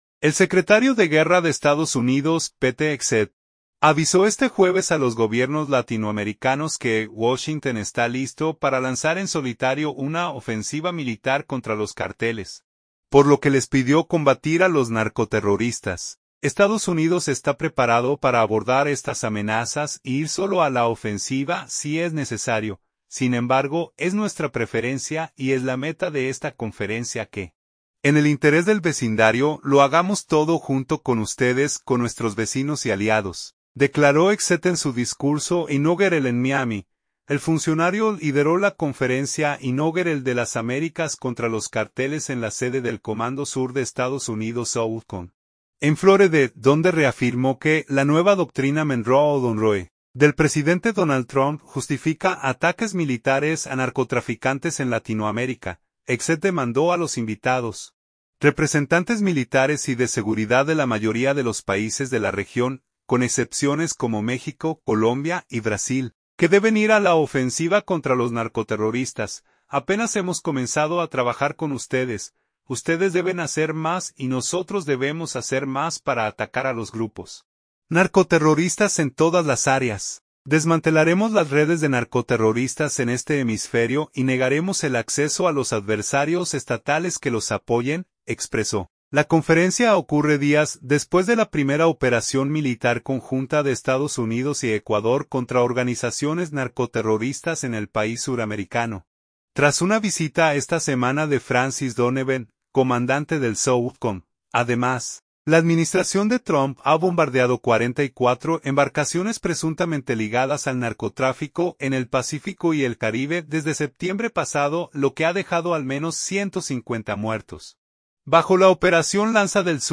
"Estados Unidos está preparado para abordar estas amenazas e ir solo a la ofensiva si es necesario. Sin embargo, es nuestra preferencia y es la meta de esta conferencia que, en el interés del vecindario, lo hagamos todo junto con ustedes, con nuestros vecinos y aliados", declaró Hegseth en su discurso inaugural en Miami.